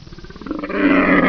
wolt_die3.wav